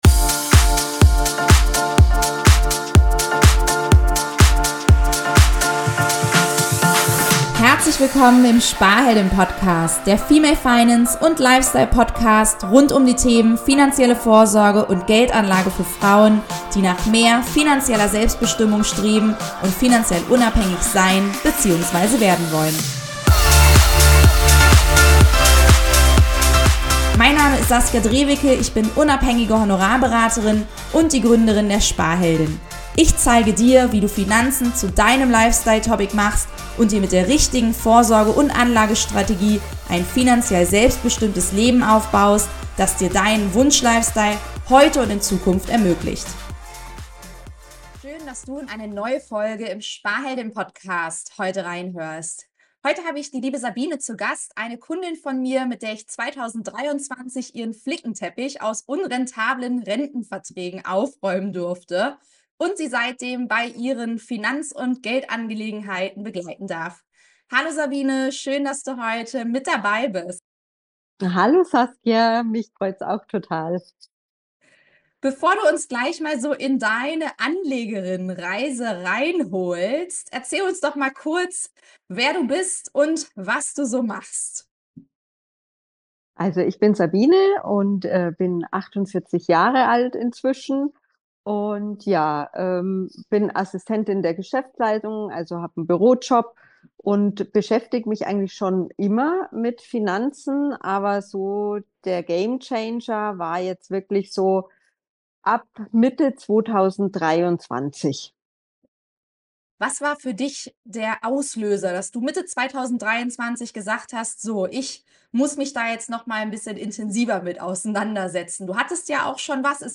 Money-Talk von Frau zu Frau